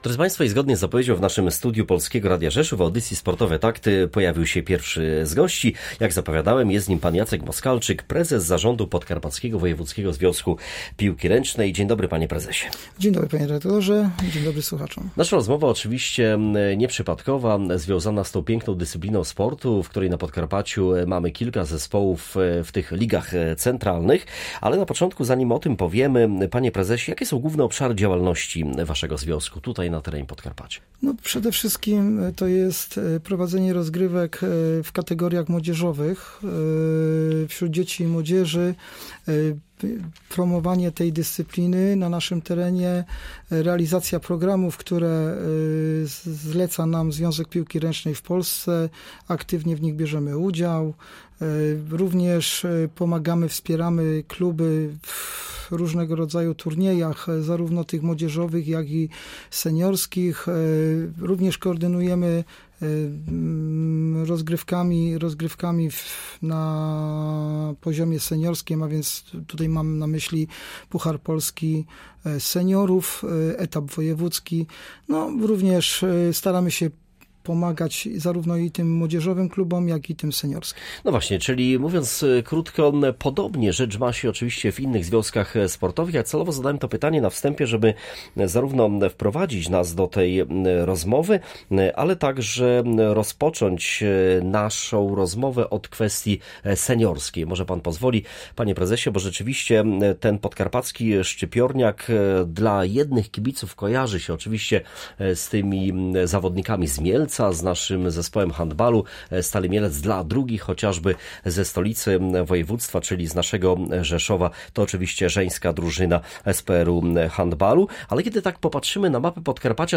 Gościem sobotniej audycji „Sportowe Takty